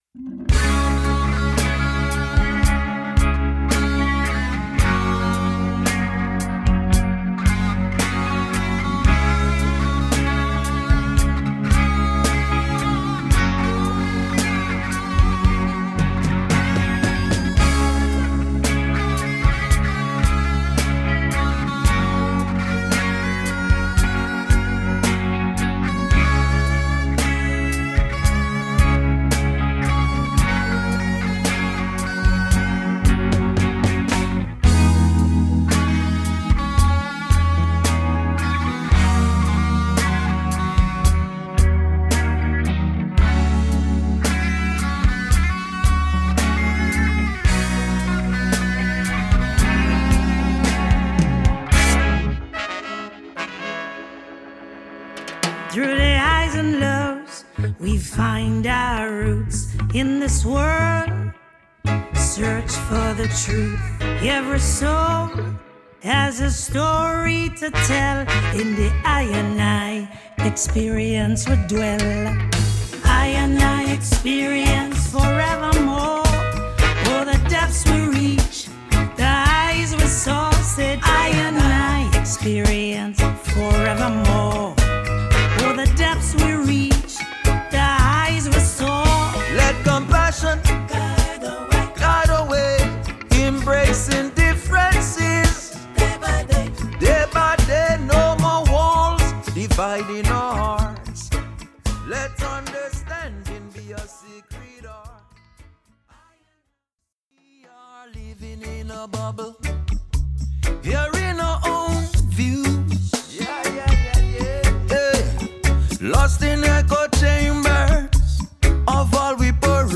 The intros are all dynamic, as well.